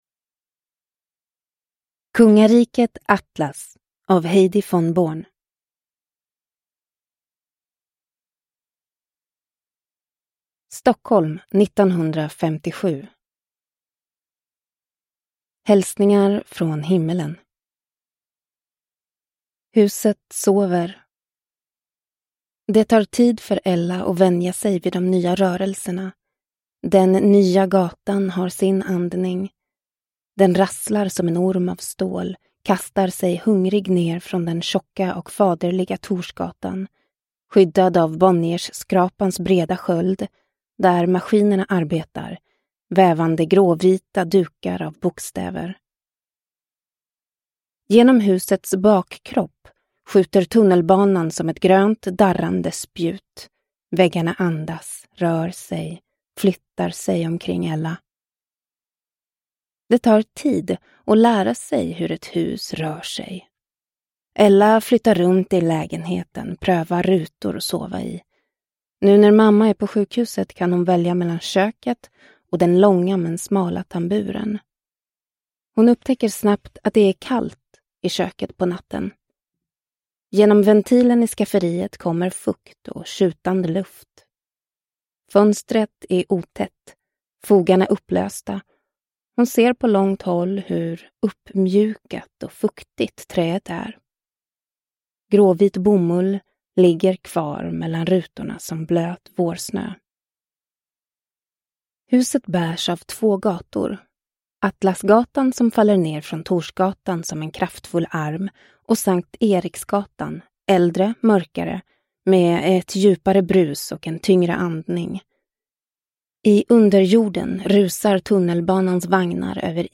Kungariket Atlas – Ljudbok – Laddas ner